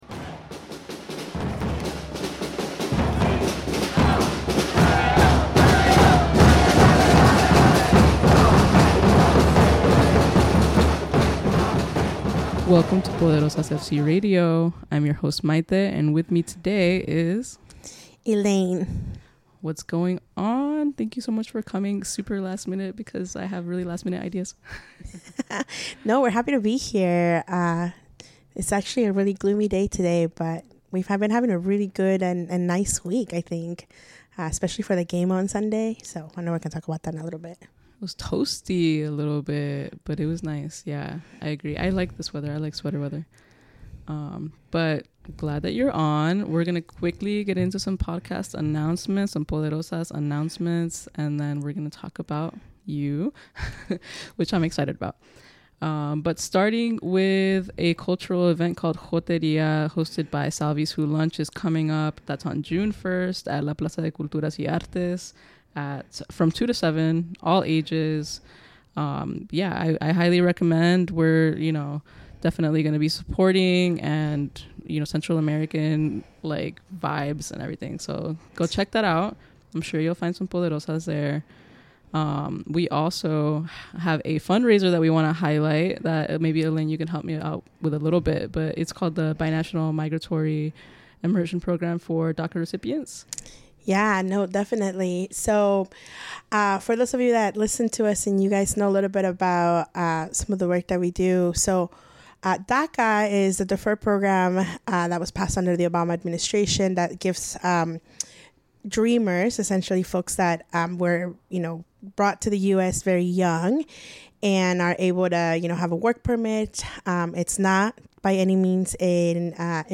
A special thank you to Espacio 1839 for the studio space.